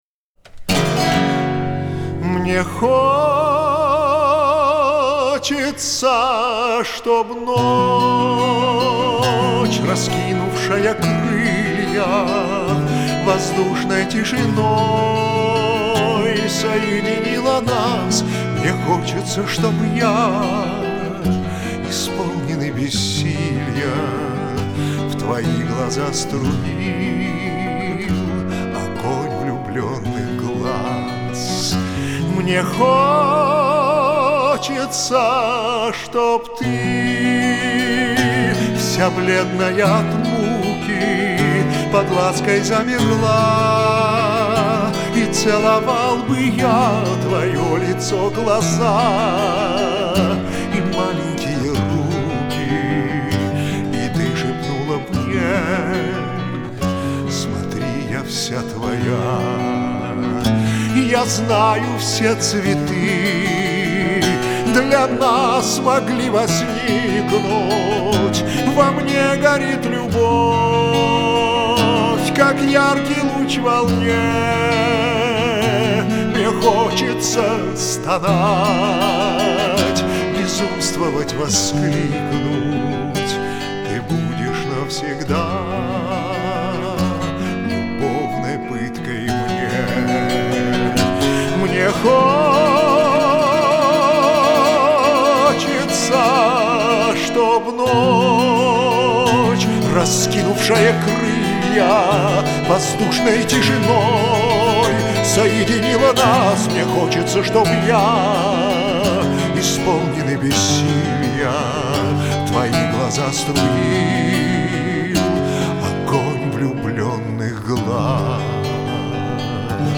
Романсы